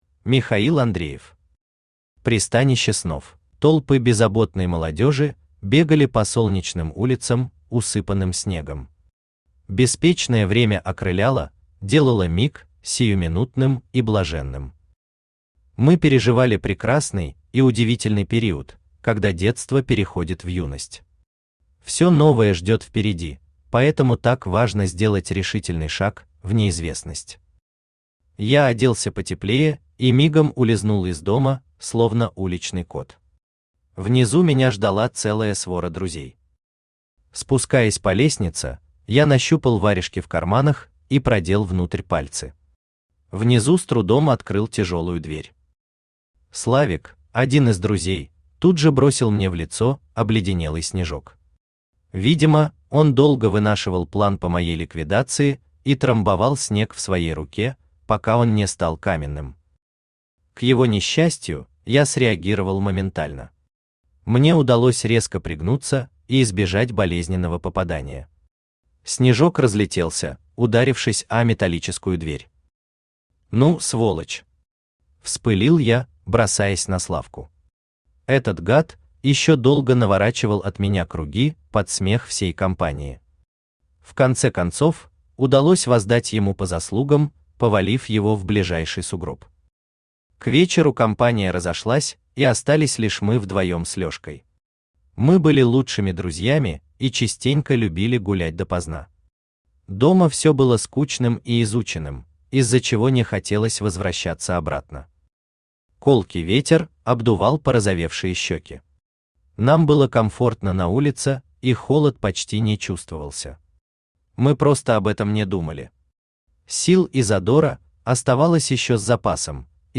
Аудиокнига Пристанище Снов | Библиотека аудиокниг
Aудиокнига Пристанище Снов Автор Михаил Андреев Читает аудиокнигу Авточтец ЛитРес.